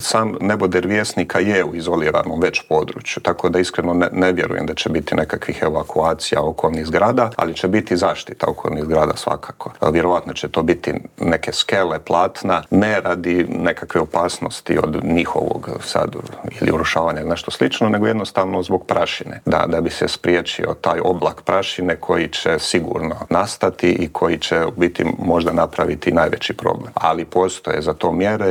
Intervjuu tjedna